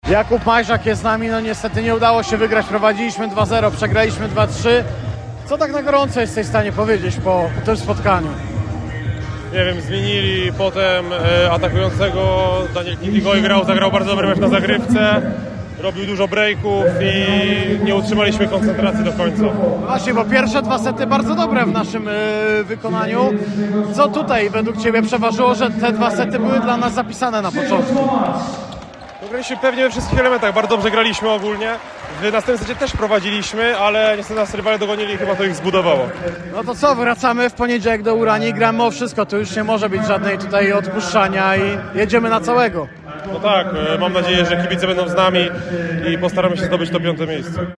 – mówił po spotkaniu w Bełchatowie